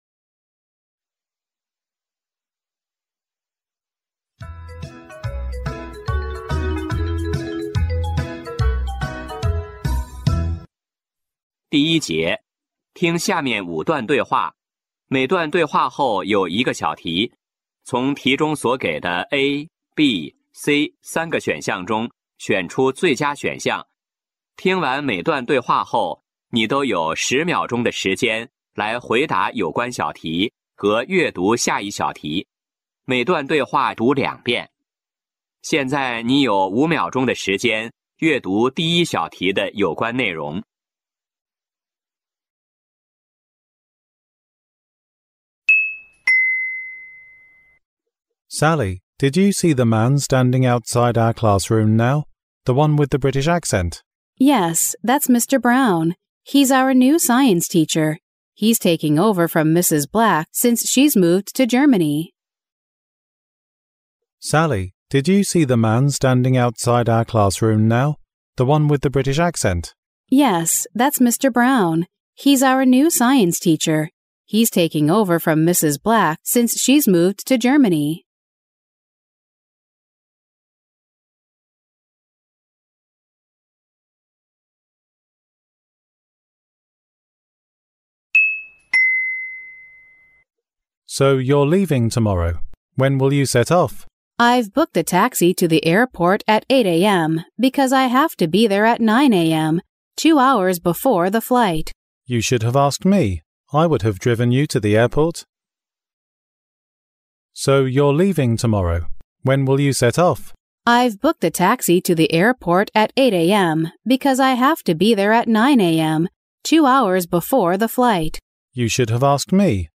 成都树德中学高2023级高三上期期末测试英语听力.mp3